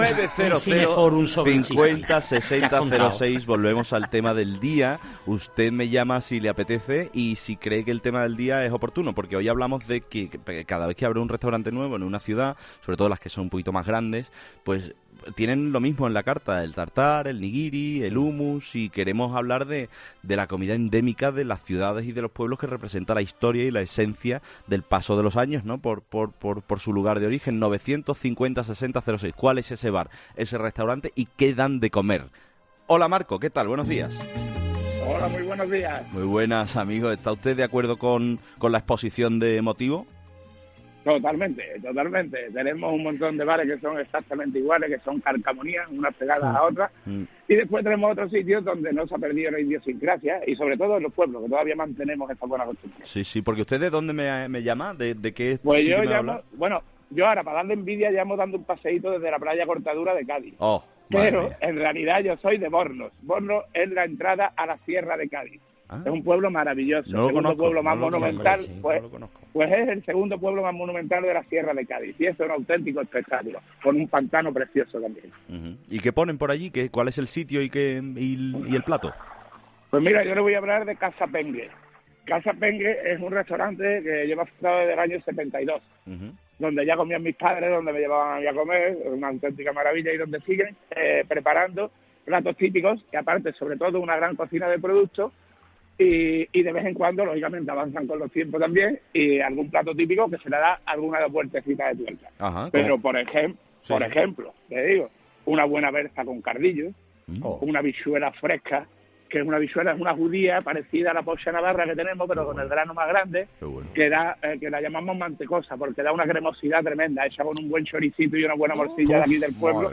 Hemos descubierto este testimonio durante 'la hora de los fósforos'. Una sección en la que nuestros oyentes nos cuentan historias de todo tipo